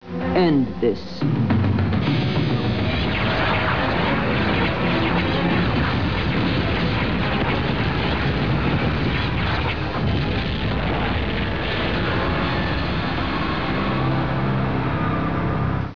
Delenn, "End this!" music, firing, and the other ship explodes.